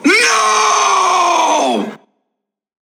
NOOOOOOOO
noooooooo_SwjpsKc.mp3